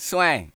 SouthSide Chant (65)(1).wav